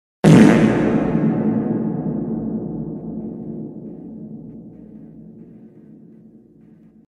Reverb fart sound effect
Tags: funny